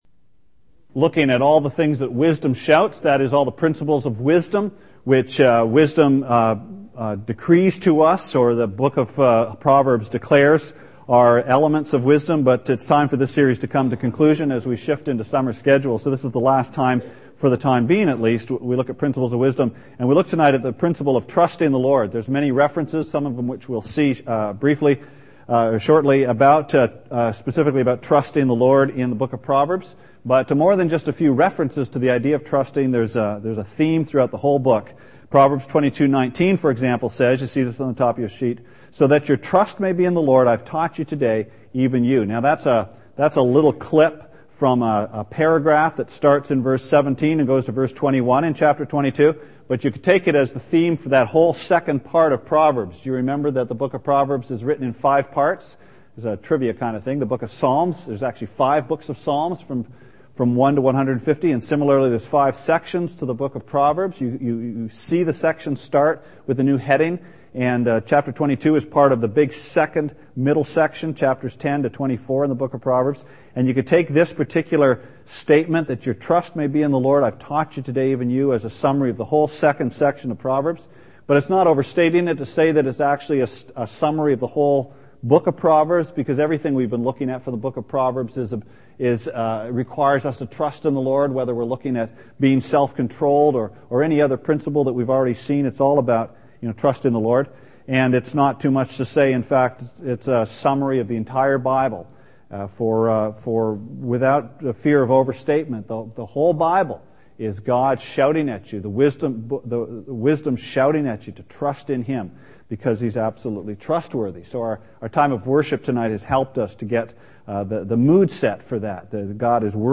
Sermon Archives - West London Alliance Church
Part 7 of a Sunday evening series on Proverbs.